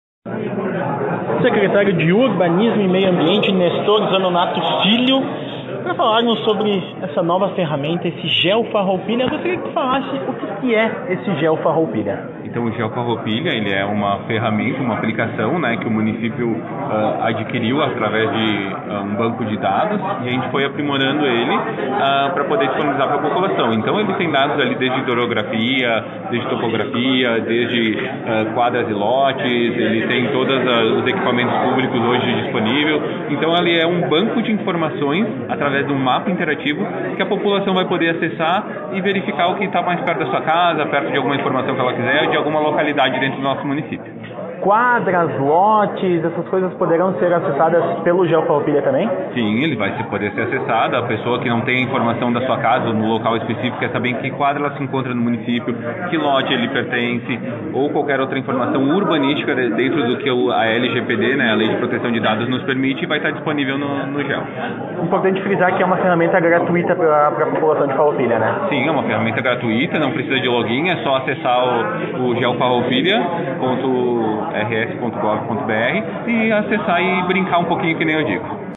Ouça o secretário de Urbanismo e Meio Ambiente, Nestor José Zanonato Filho